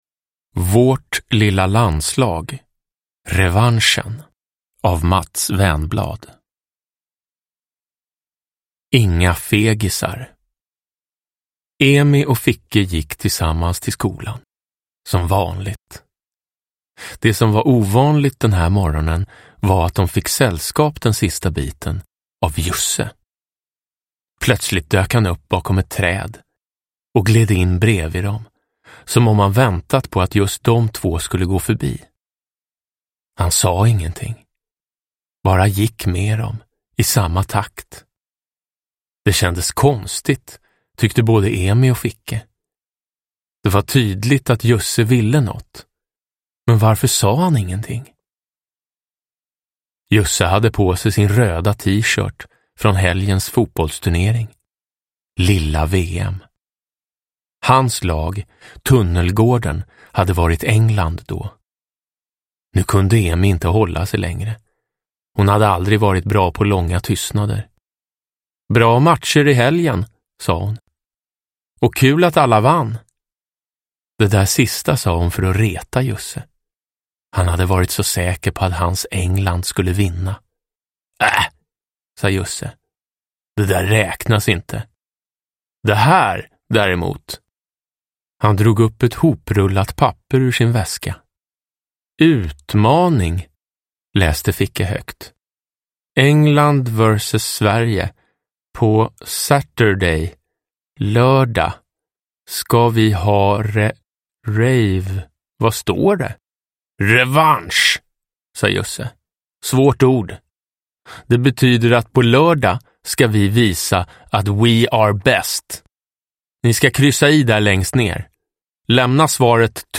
Revanschen – Ljudbok – Laddas ner
Uppläsare: Jonas Karlsson